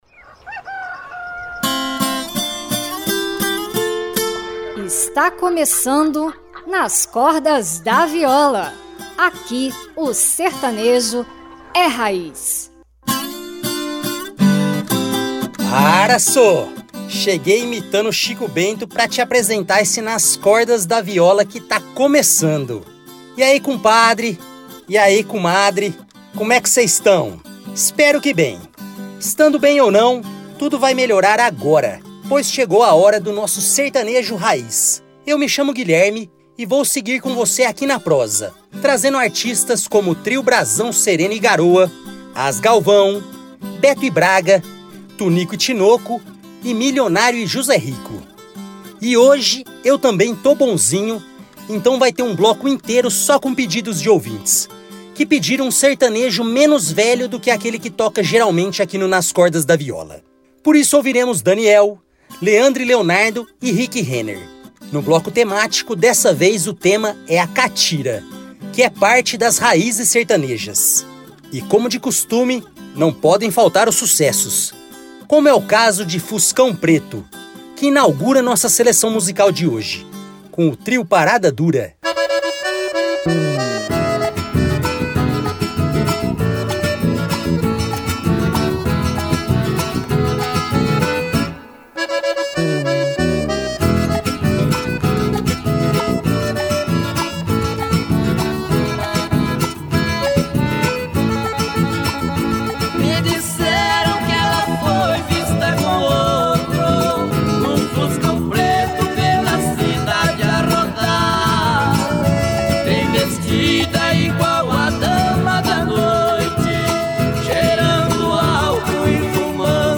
o programa à moda caipira.
teremos um bloco só com sertanejo dos anos 1990 e 2000
E, por fim, um bloco só com músicas no ritmo da Catira.